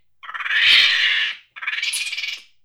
c_mnky_atk3.wav